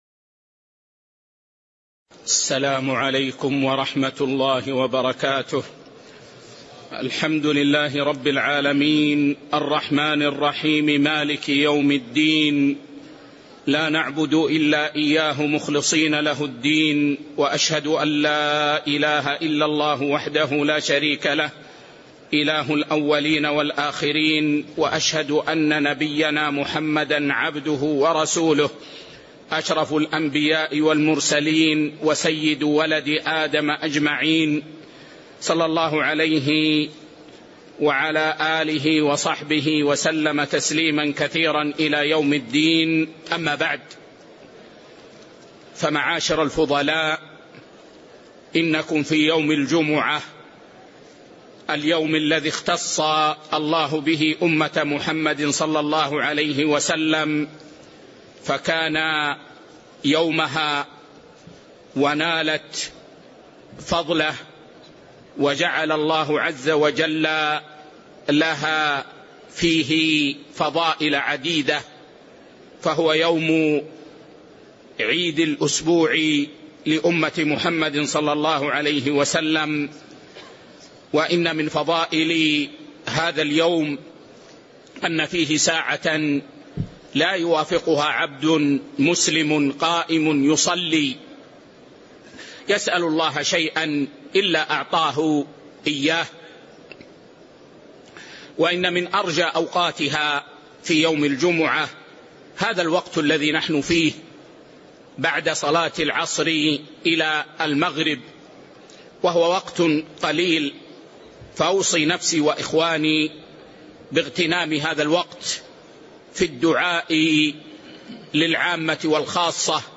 تاريخ النشر ٢٨ شعبان ١٤٤٥ هـ المكان: المسجد النبوي الشيخ